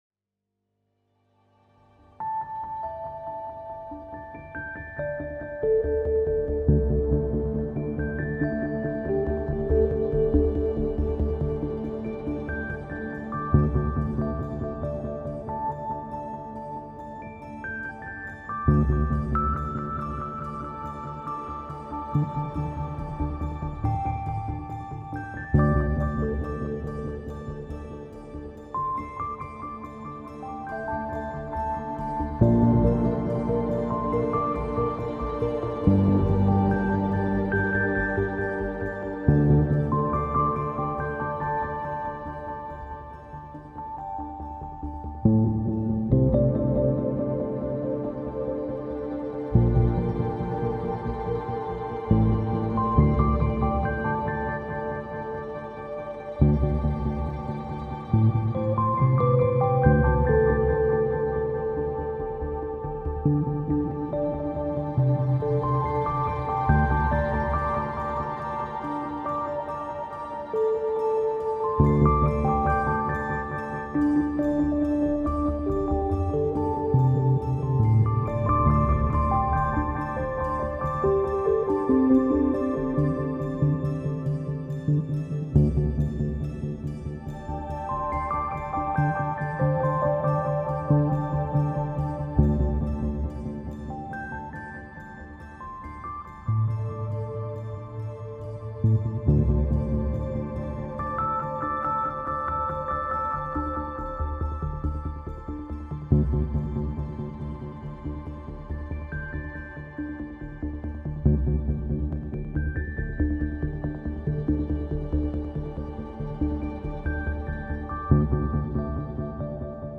A melodious softening of an atmospheric pulse.